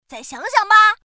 huo_error.mp3